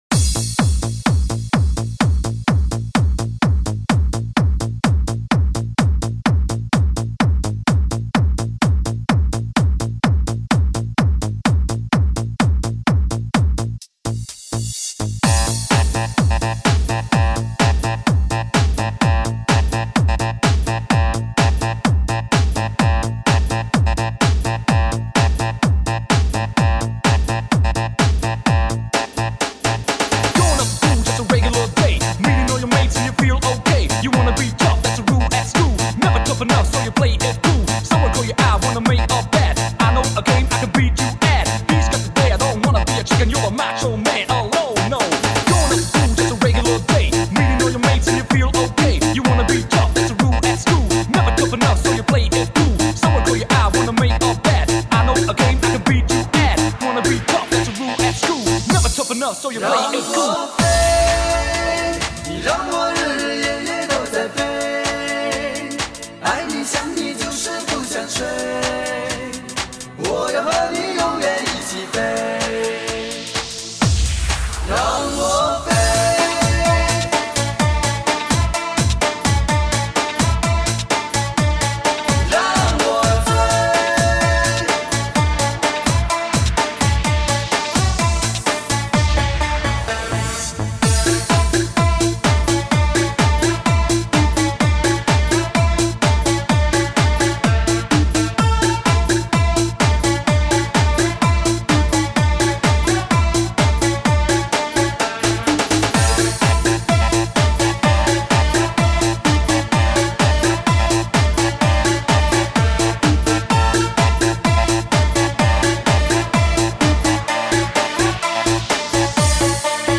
中国最具实力汽车音响测试碟，聆听最纯粹的3D极致环绕音场。
最新【DJ舞曲】最前卫的音乐！